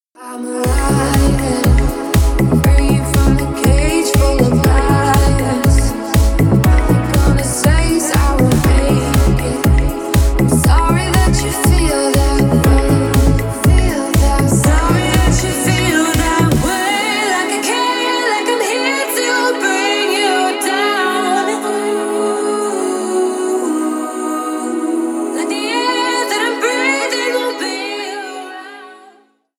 Ремикс # Поп Музыка